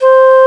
20FLUTE01 -R.wav